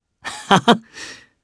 Clause-Vox-Laugh_jp.wav